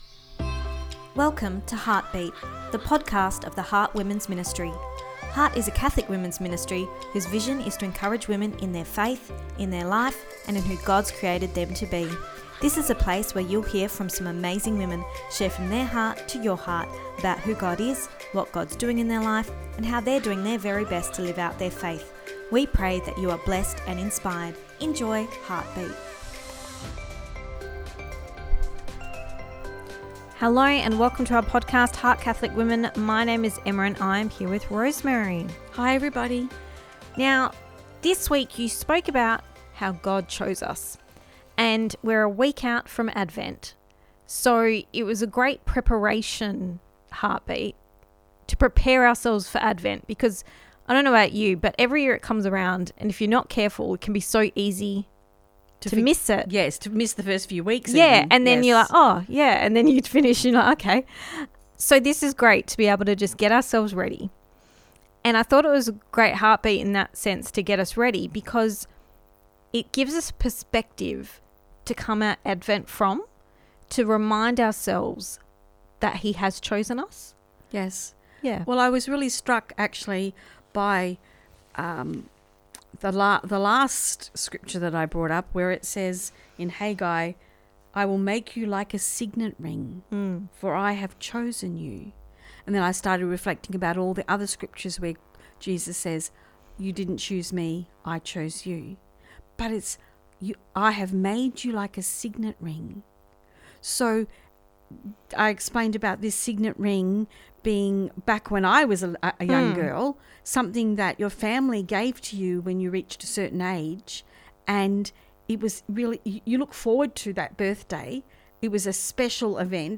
Ep274 Pt2 (Our Chat) – He Chose You